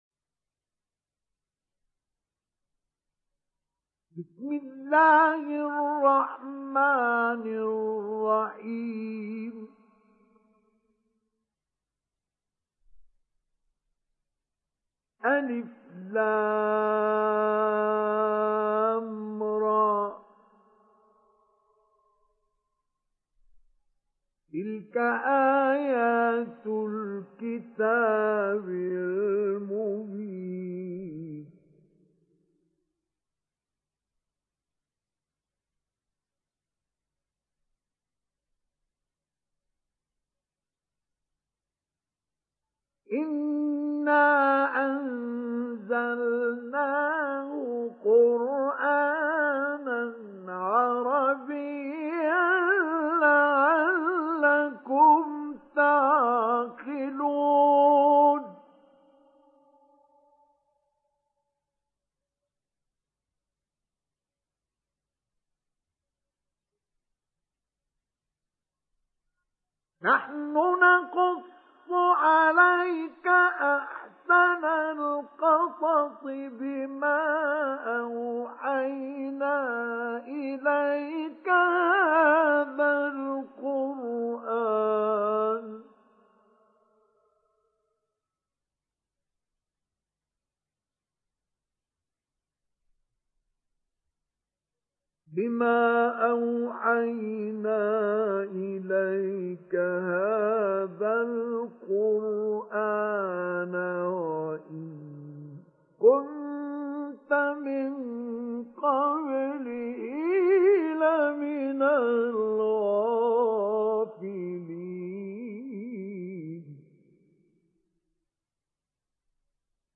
Sourate Yusuf Télécharger mp3 Mustafa Ismail Mujawwad Riwayat Hafs an Assim, Téléchargez le Coran et écoutez les liens directs complets mp3
Télécharger Sourate Yusuf Mustafa Ismail Mujawwad